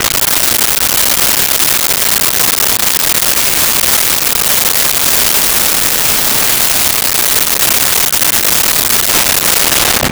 Boat Sail Hoist 01
Boat Sail Hoist 01.wav